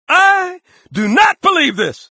Halo Dialogue Snippets
idonotbelievethis_stack.mp3